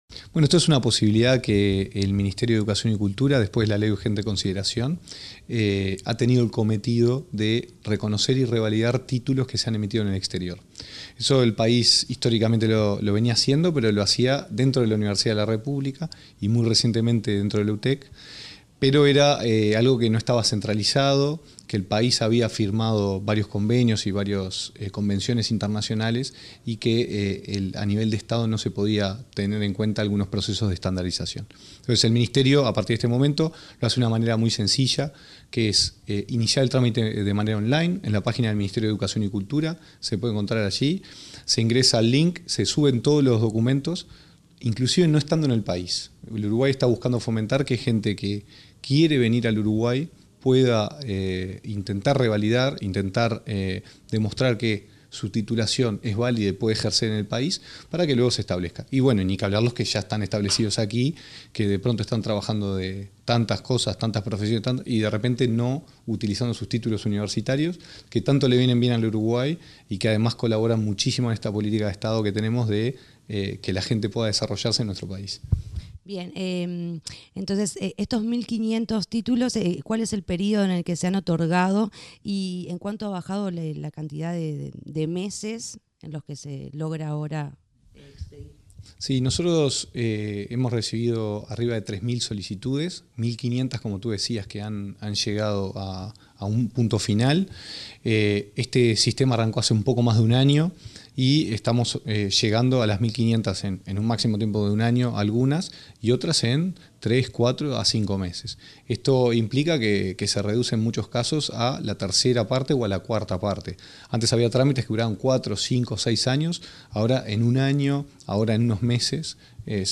Entrevista al director nacional de Educación, Gonzalo Baroni